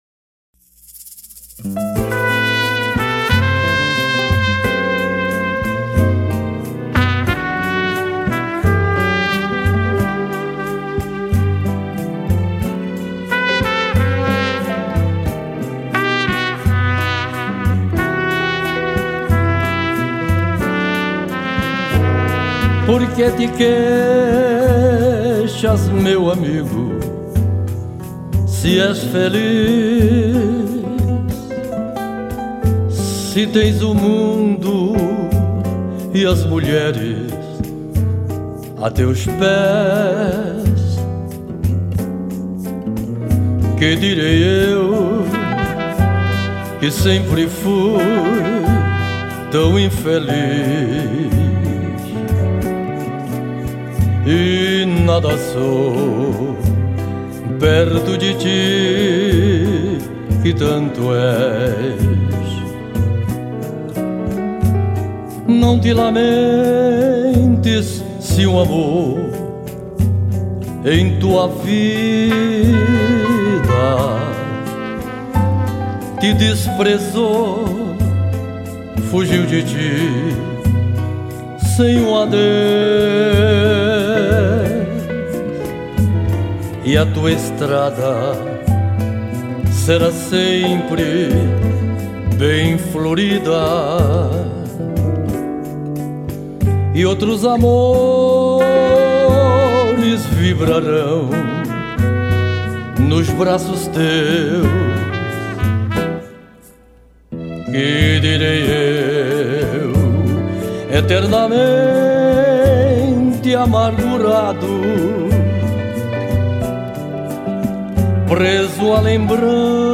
2284   04:11:00   Faixa:     Bolero